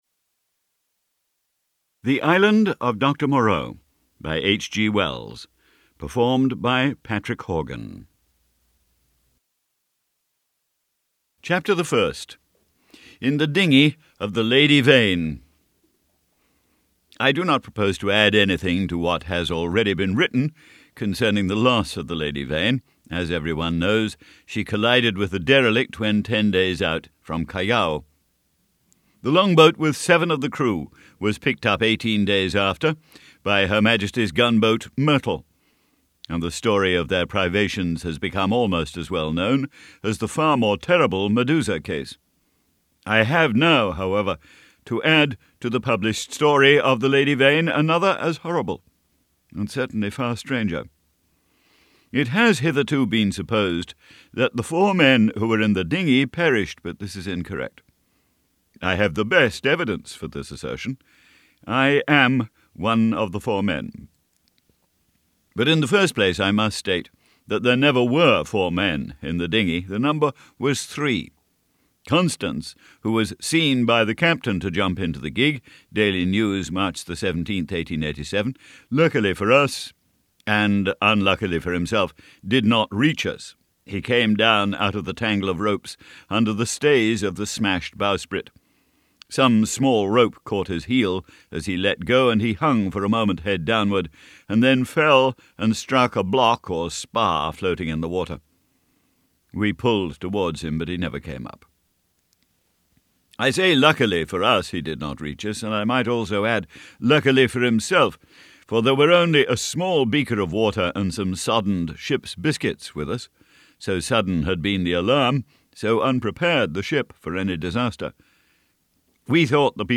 The Island of Dr. Moreau by H. G. Wells, unabridged audiobook mp3 d/l